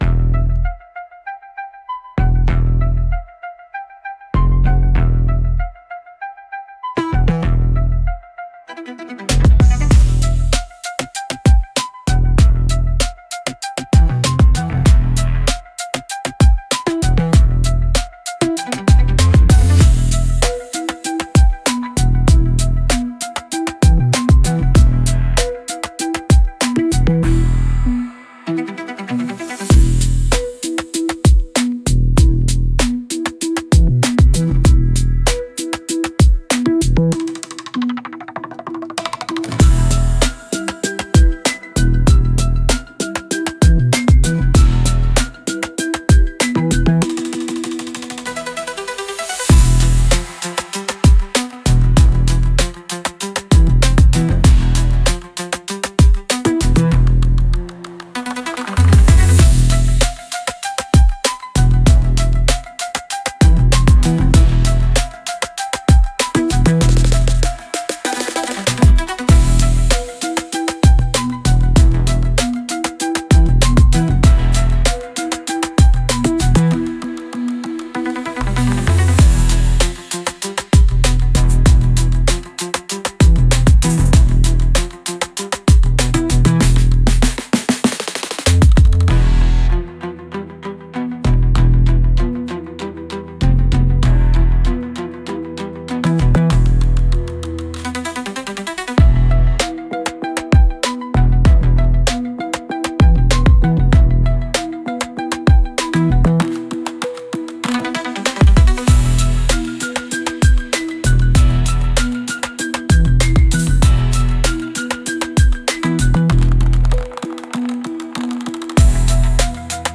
Instrumental - Static Heart- 2.23 Mins